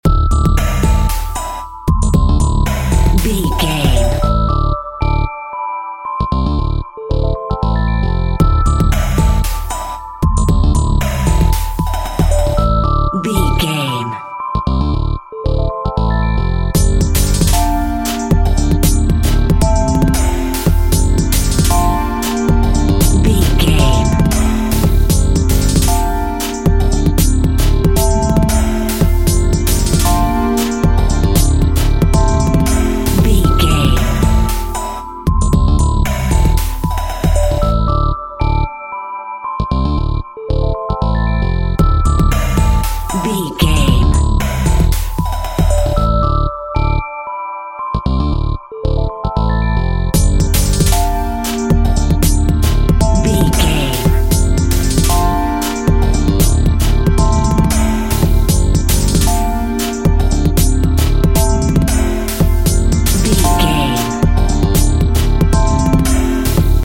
Computer Music Theme.
Aeolian/Minor
Fast
groovy
uplifting
futuristic
driving
energetic
synthesiser
drum machine
electric piano
electronic
synth lead
synth bass